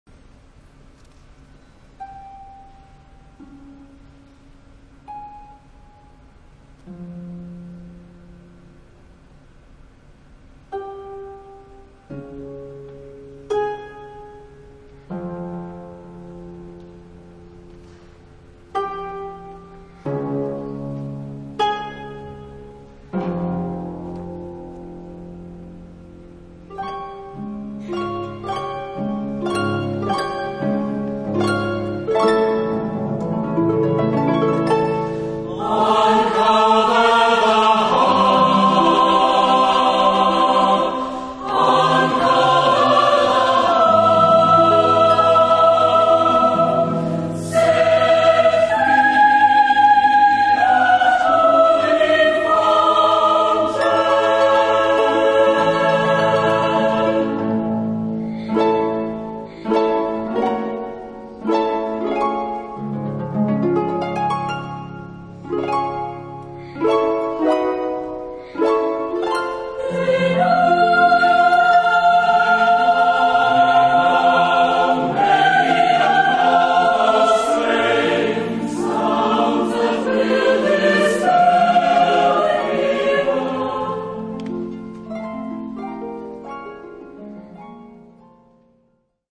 For solo soprano, SATB chorus and two harps.